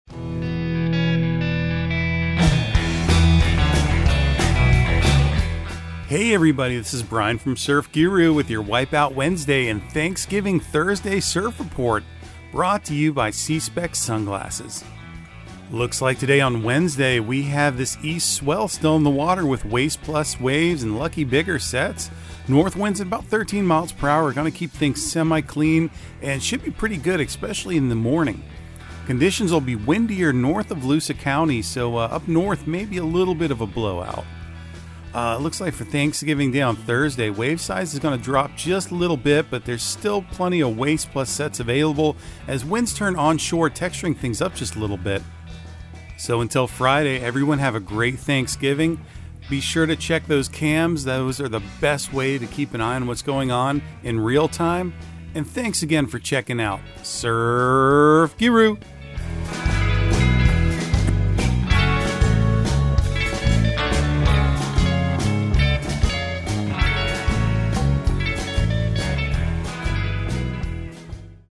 Surf Guru Surf Report and Forecast 11/23/2022 Audio surf report and surf forecast on November 23 for Central Florida and the Southeast.